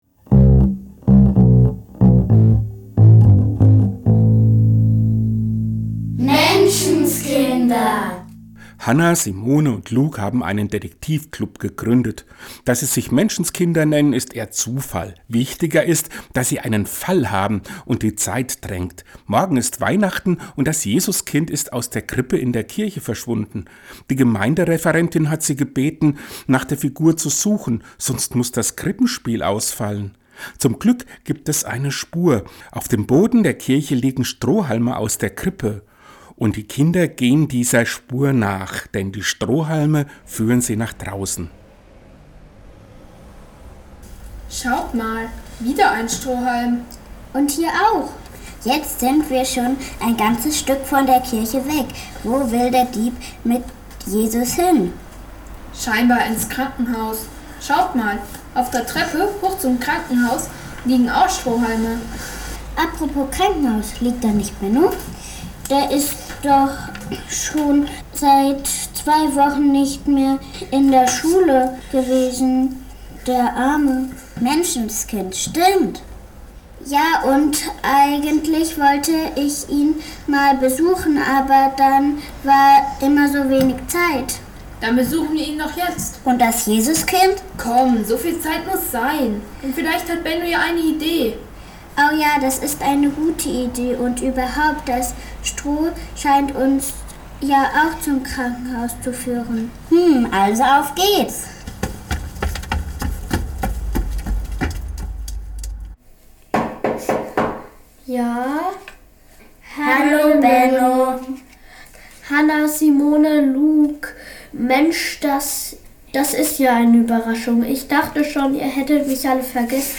Die Weihnachtsdetektive Teil 2 Hörspiel im Advent Seit letztem Sonntag sind bei uns drei Detektive unterwegs.